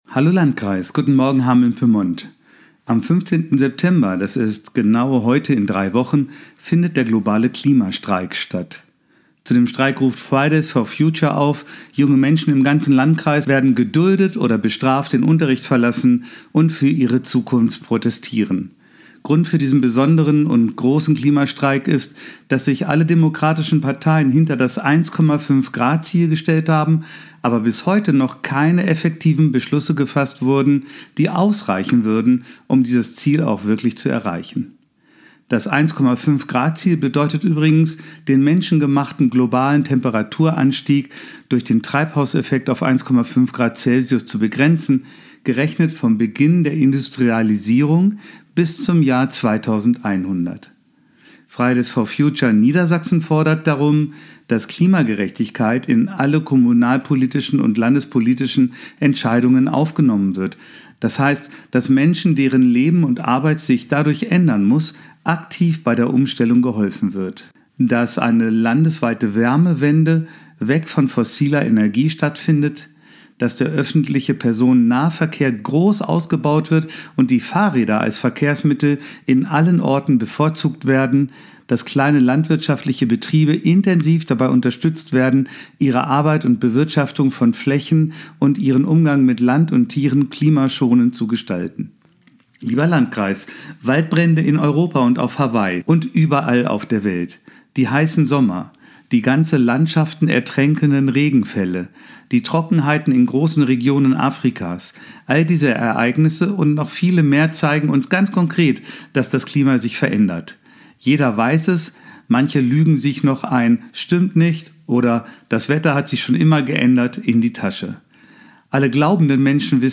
Radioandacht vom 25. August